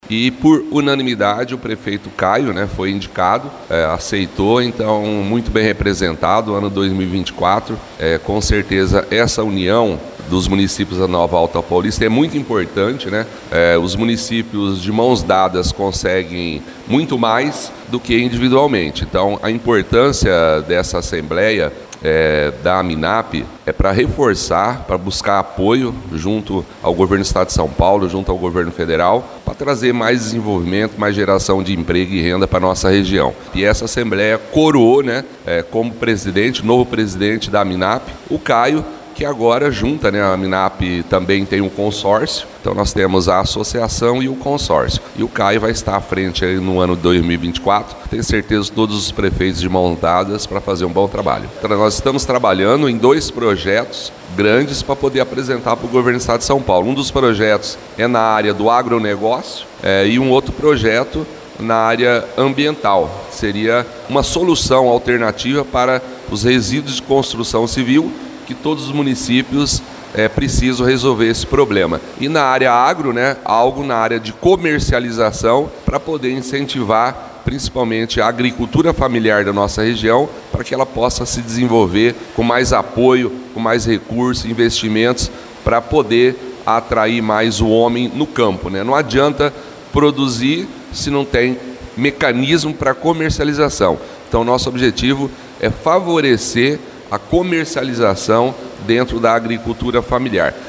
O prefeito de Adamantina, Márcio Cardim é o atual presidente da entidade, disse que a condução de Caio Aoqui foi por unanimidade. ouça áudio